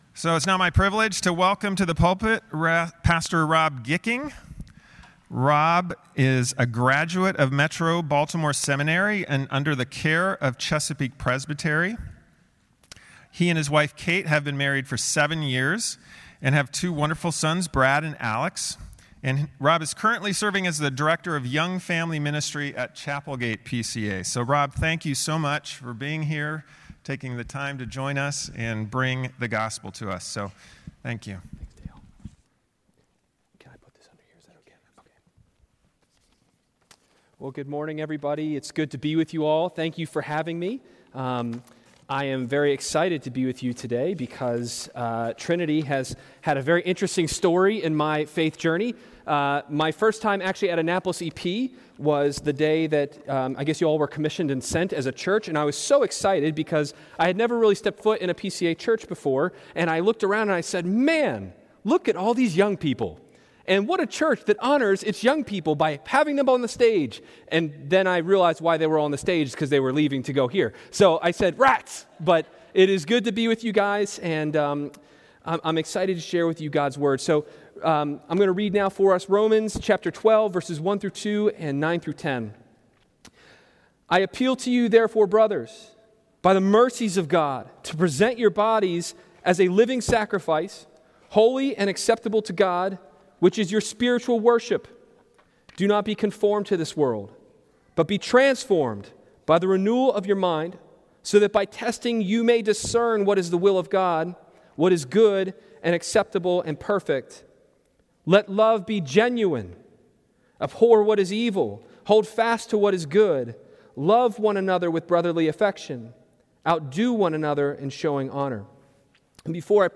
From Series: "Guest Sermons"